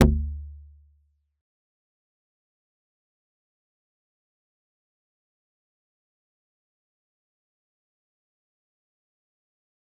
G_Kalimba-G1-mf.wav